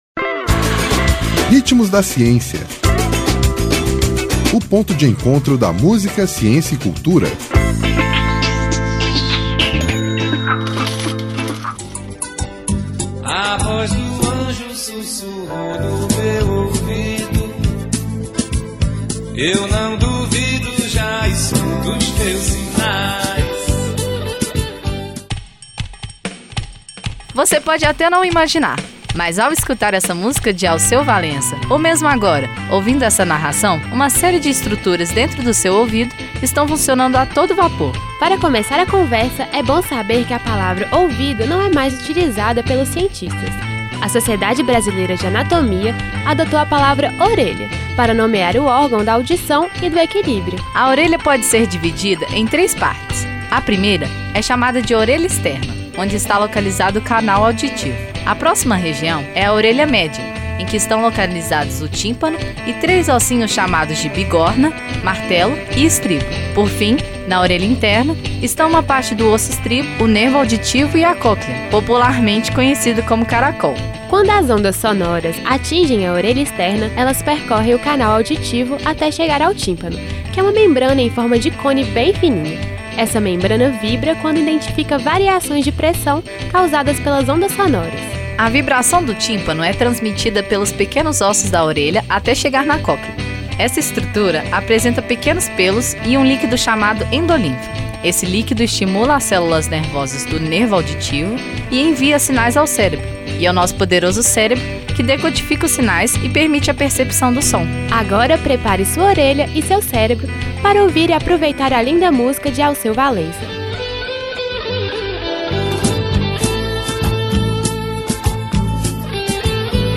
Vozes
Intérprete: Alceu Valença